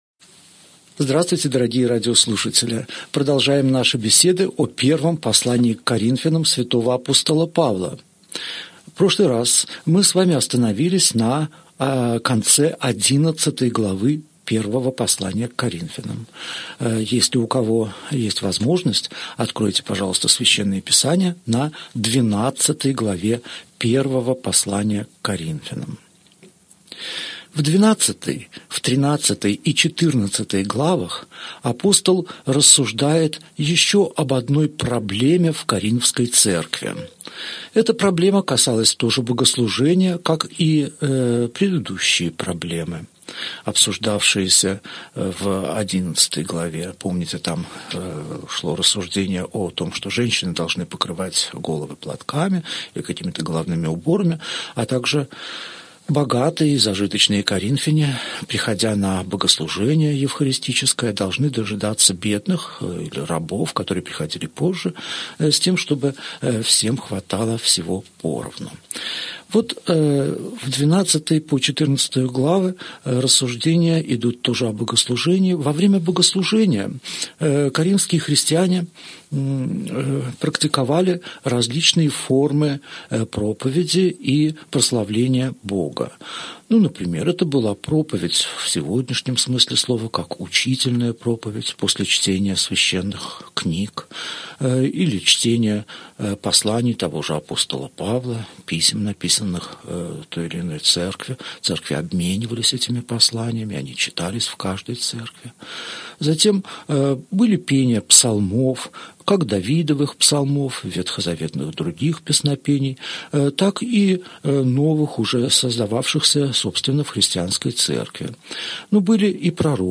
Аудиокнига Беседа 31. Первое послание к Коринфянам. Глава 12 | Библиотека аудиокниг